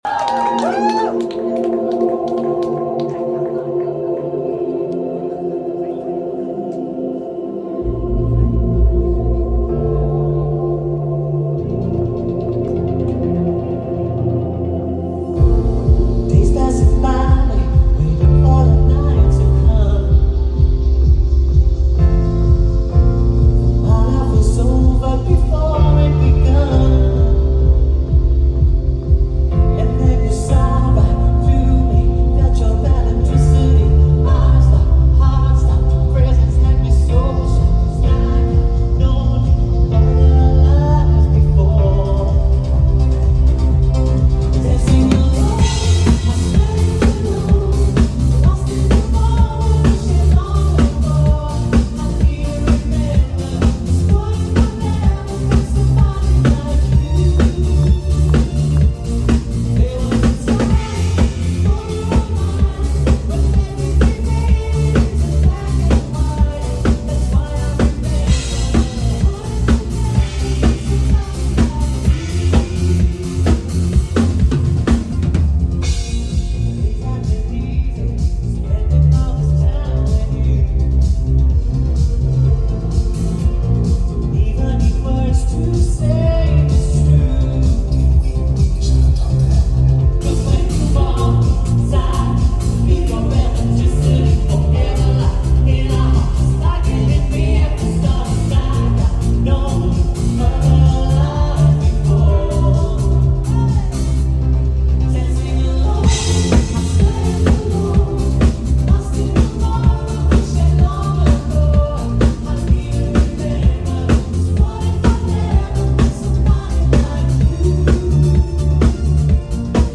Kavka zappa in Antwerpen(30.05.2025)